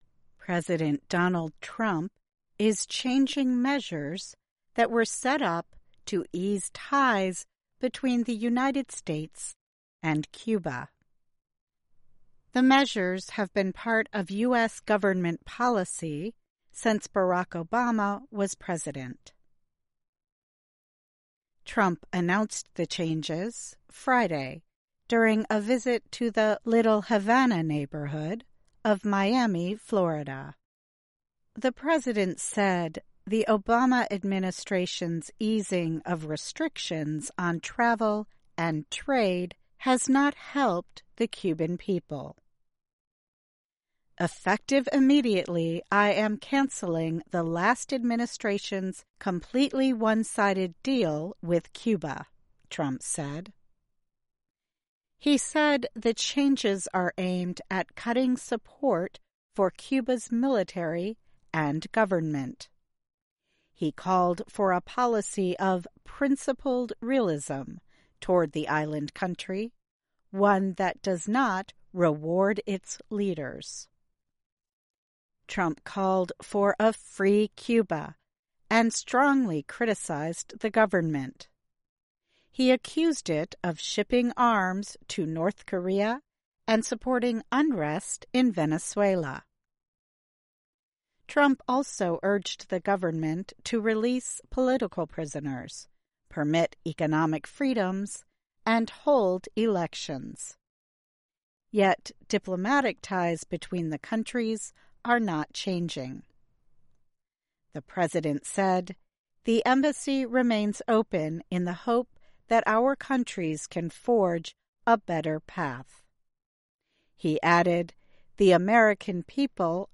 慢速英语:Trump Announces Cuba Policy Changes